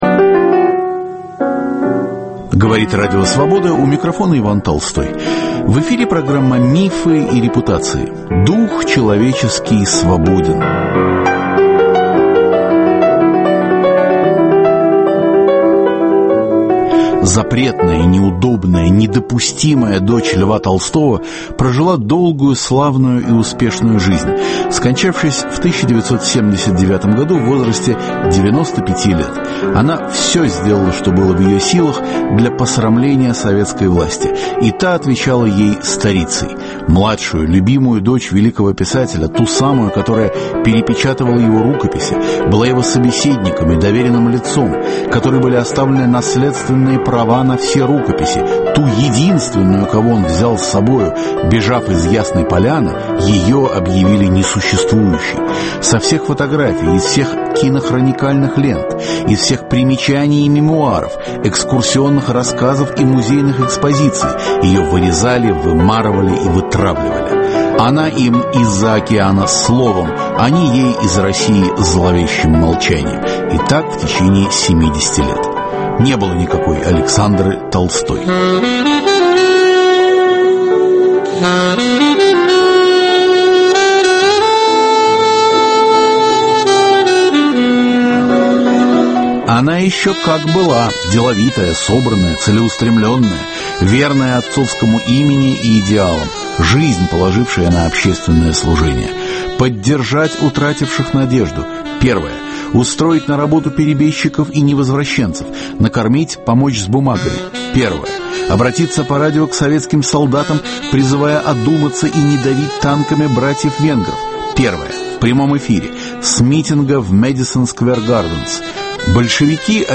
В программе звучат голоса историков и архивные записи разных лет, где Александра Толстая рассказывает о себе сама.